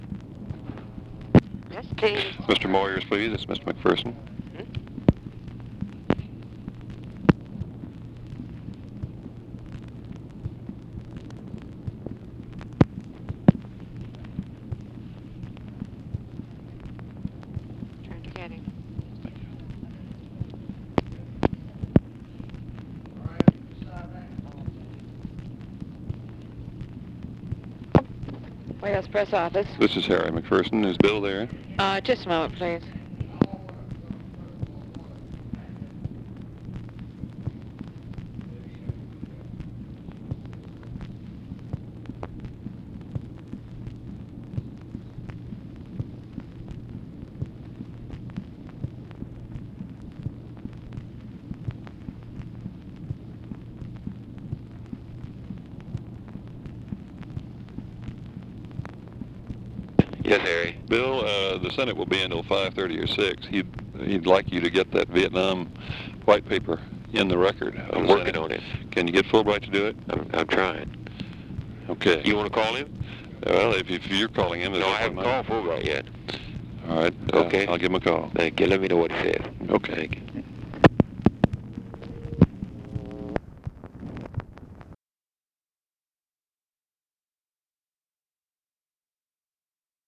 Conversation with BILL MOYERS, HARRY MCPHERSON and OFFICE CONVERSATION
Secret White House Tapes